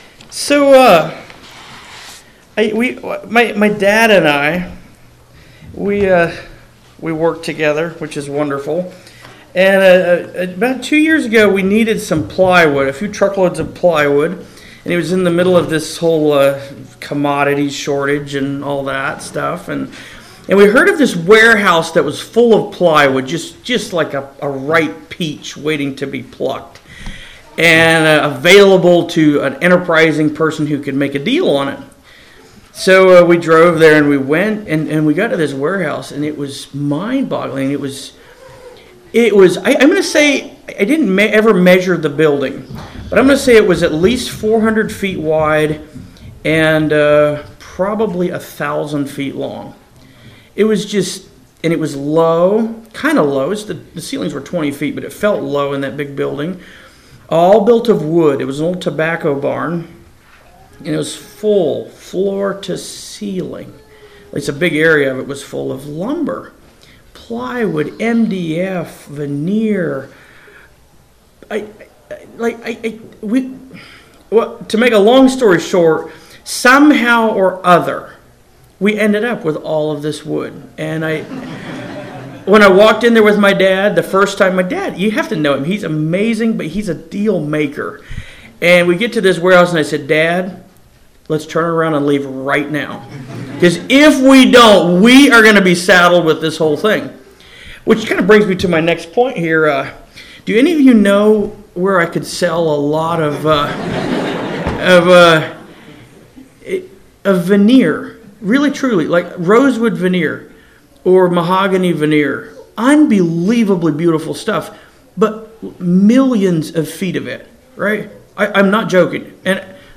2025 Midwest Teachers Week 2025 Recordings Playing the Long Game Audio 00:00